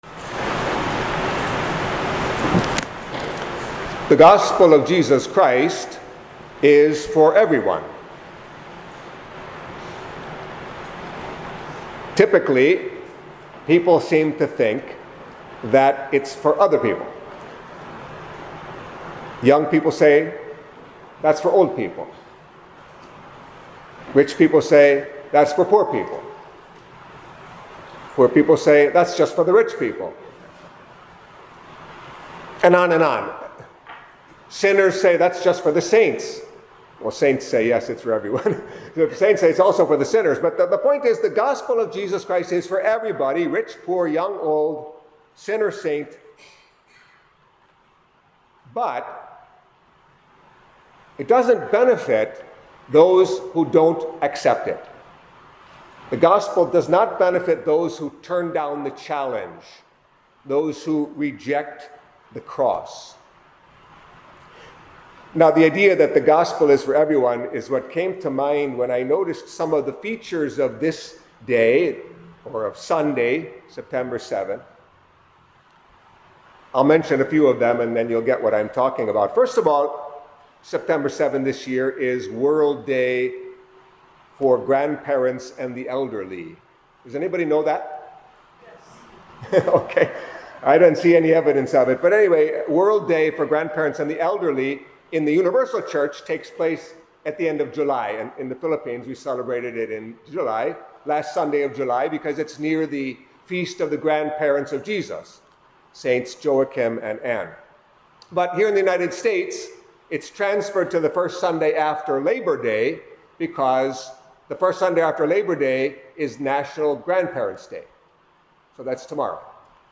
Catholic Mass homily for Sunday of the Twenty-Third Week in Ordinary Time